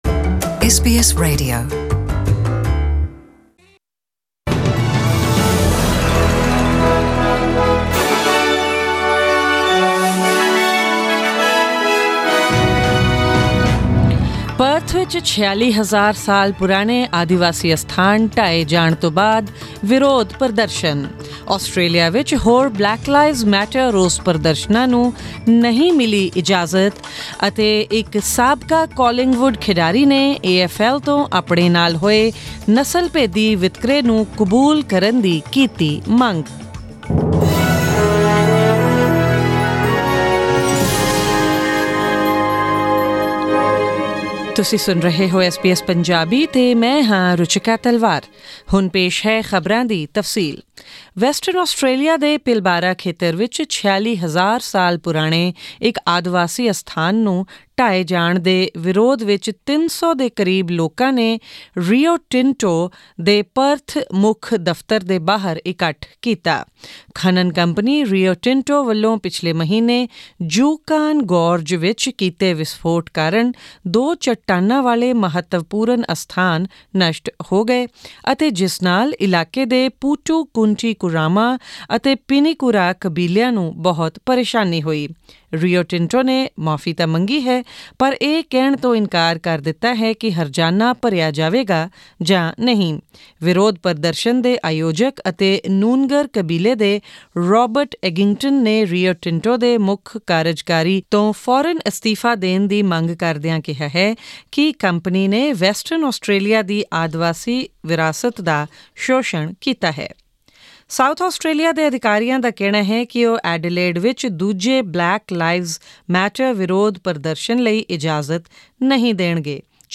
Australian News in Punjabi: 9 June 2020